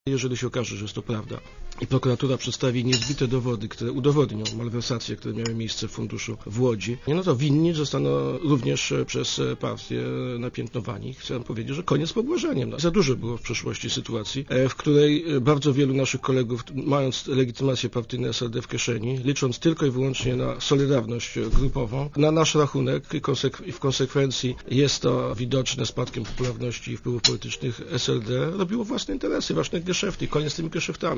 Jeżeli to prawda to rozumiem, ze prokuratura wystąpi o uchylenie immunitetu posła Andrzeja Pęczaka – powiedział w Radiu ZET partyjny kolega posła SLD, Zbigniew Siemiątkowski.